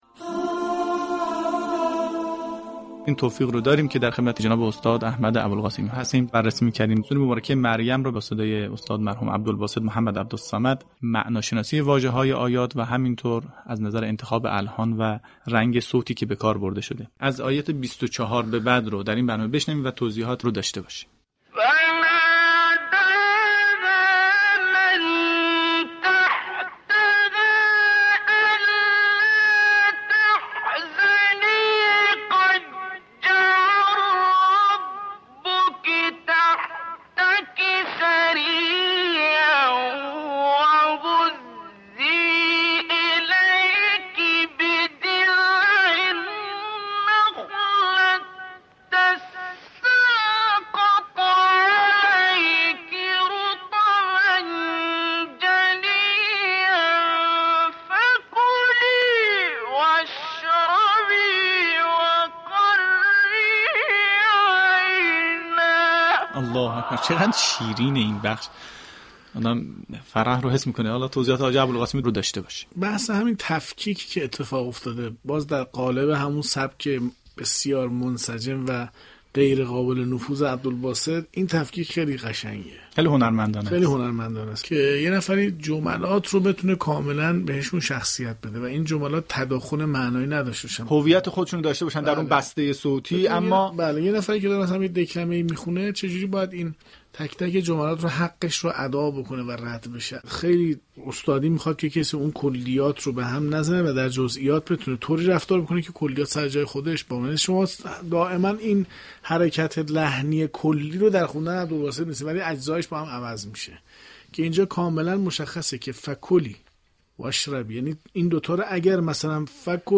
ترنم آیات | هنرمندی عبدالباسط در تلاوت آیات 24 تا 26 سوره مریم؛ ظرافت‌های لحنی بی‌نقص
وی افزود: عبدالباسط با انتخاب دقیق لحون و رنگ صوتی، موفق شده است جملات را به گونه‌ای اجرا كند كه نه تنها معنای هر بخش حفظ شود، بلكه حس و فضای آن نیز به شنونده منتقل گردد.